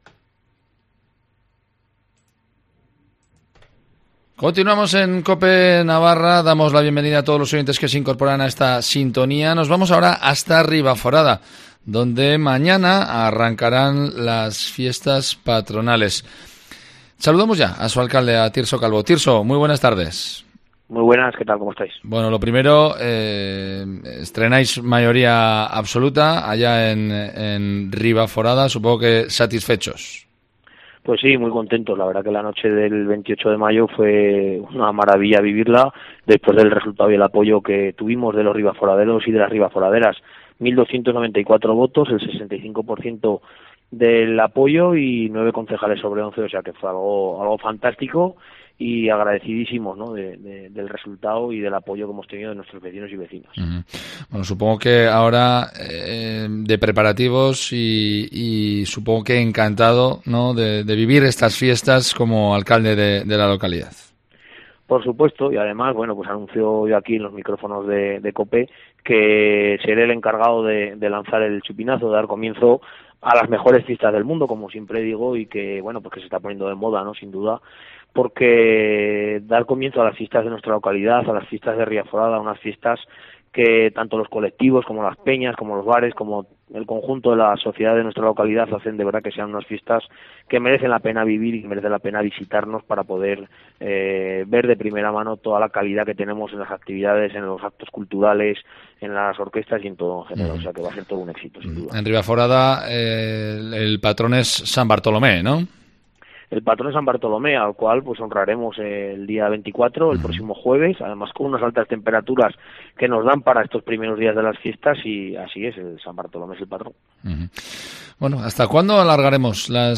AUDIO: Entrevista a Tirso Calvo, alcalde de Ribaforada.